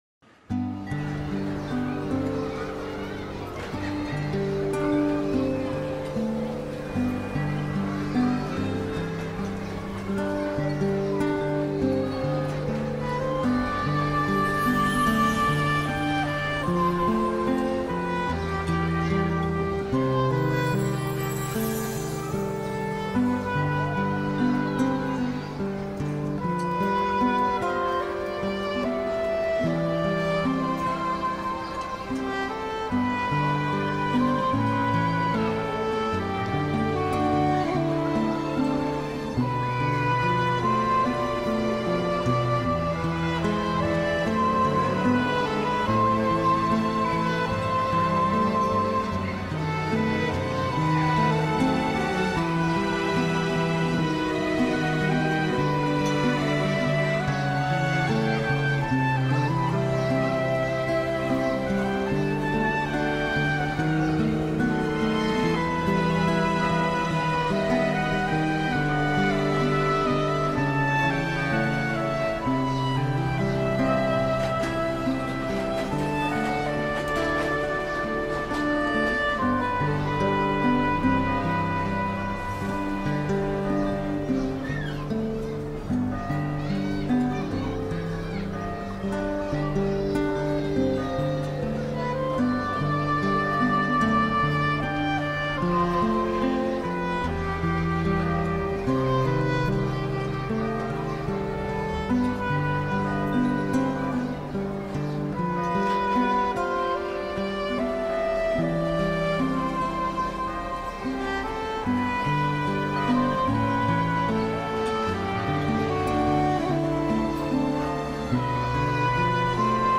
your ultimate destination for calming vibes, chill beats